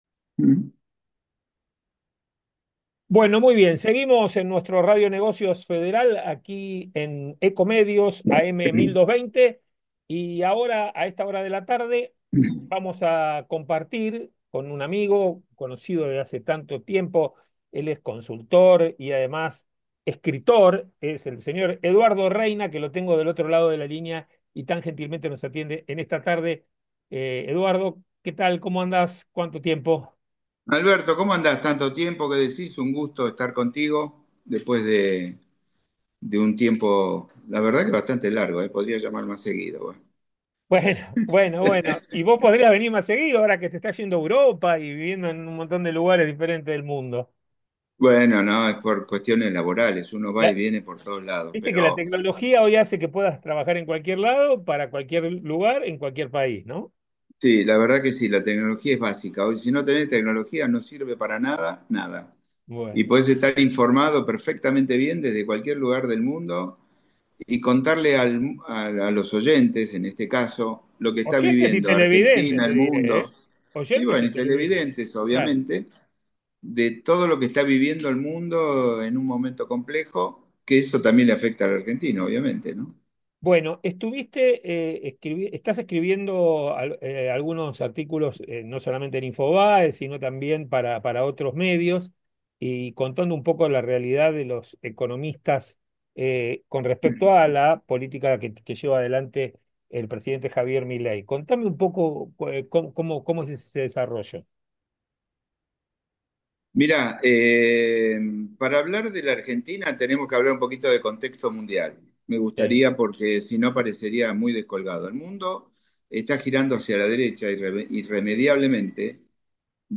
Resumen de la conversación: